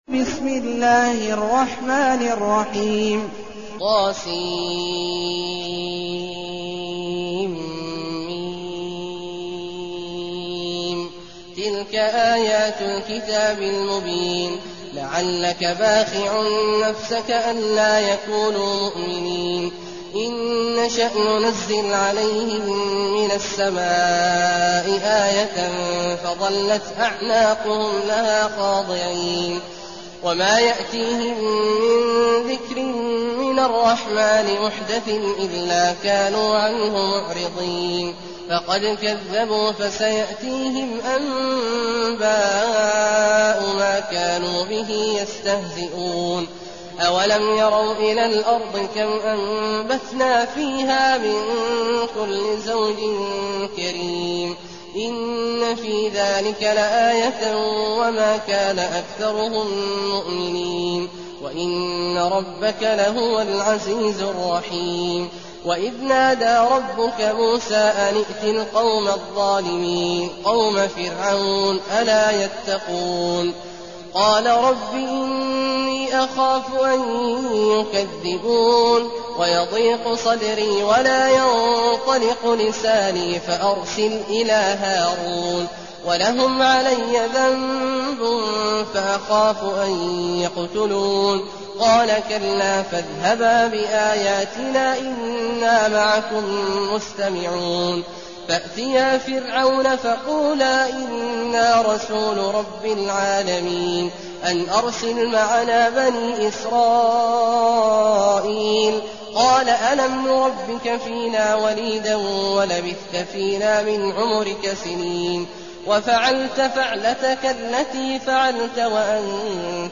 المكان: المسجد النبوي الشيخ: فضيلة الشيخ عبدالله الجهني فضيلة الشيخ عبدالله الجهني الشعراء